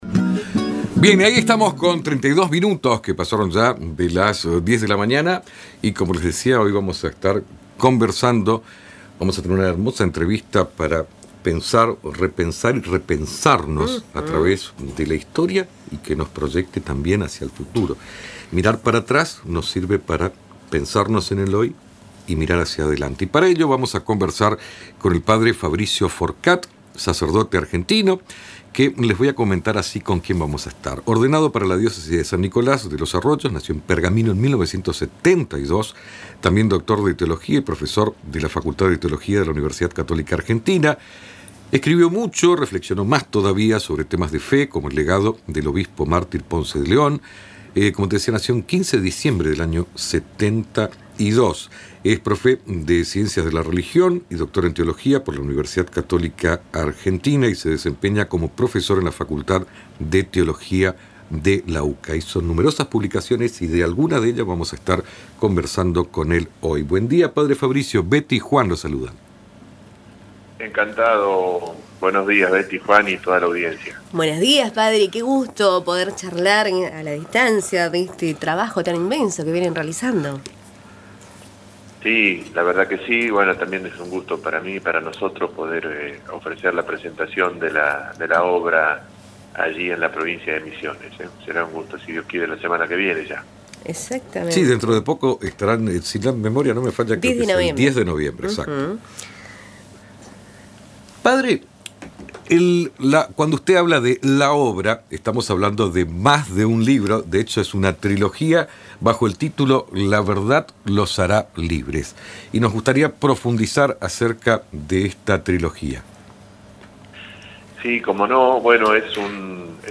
quien dialogó con Radio Tupambaé en una entrevista previa a la presentación.